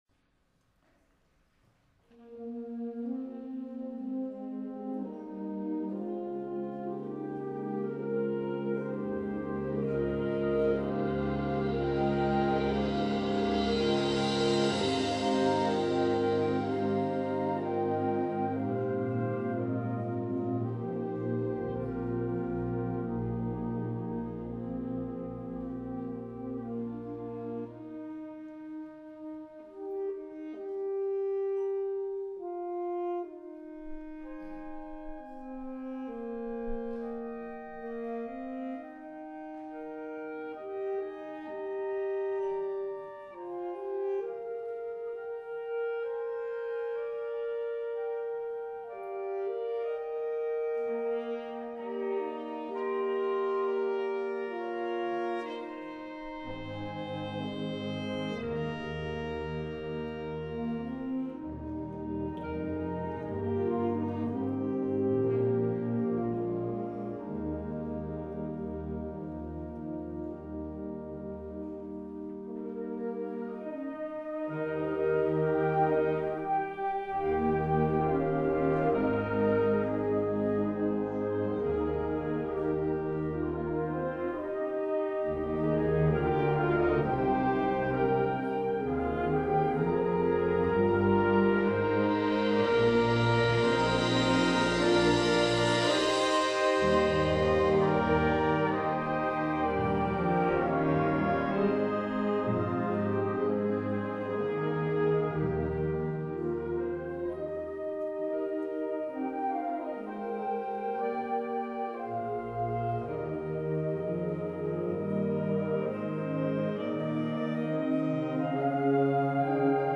2017 Advanced Festival Performance: